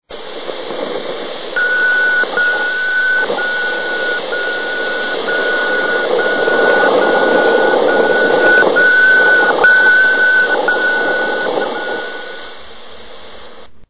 Vostok-5 biomed radio beacon